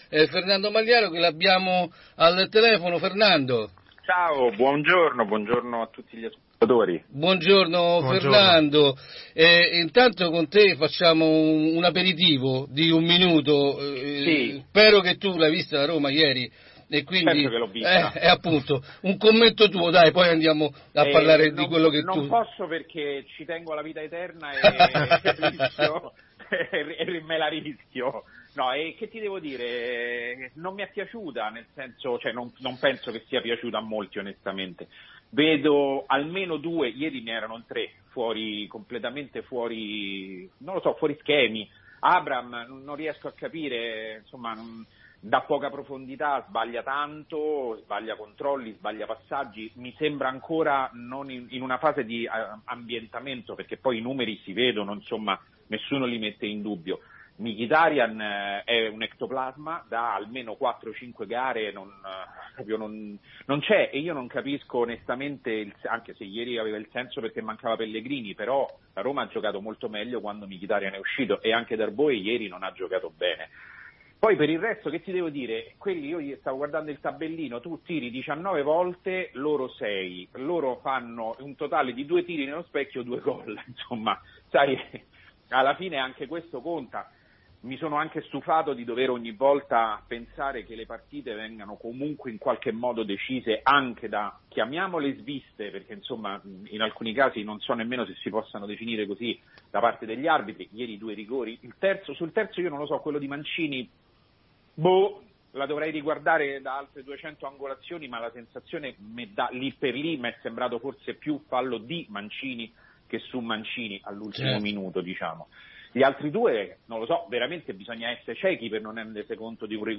La redazione vi sottopone l’audio dell’intervista originale: